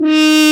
Index of /90_sSampleCDs/Roland LCDP12 Solo Brass/BRS_French Horn/BRS_Mute-Stopped